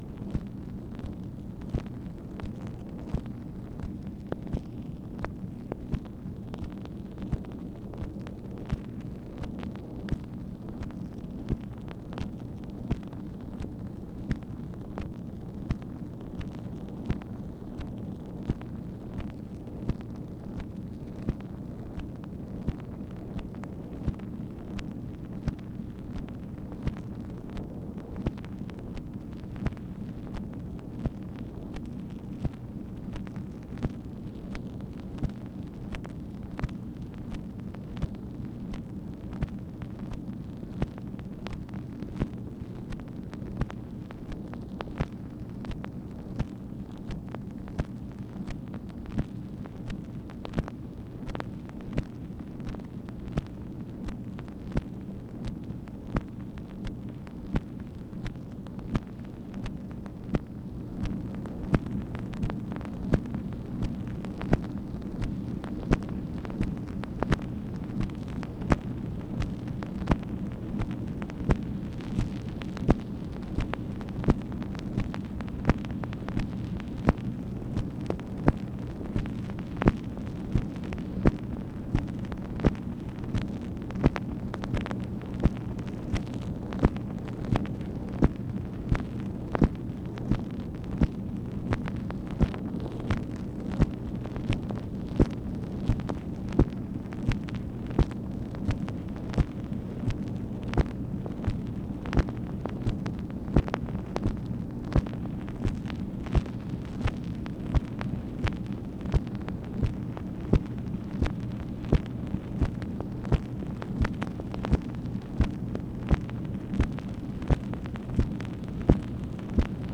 MACHINE NOISE, July 25, 1966
Secret White House Tapes | Lyndon B. Johnson Presidency